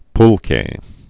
(plkā, -kē, pl-)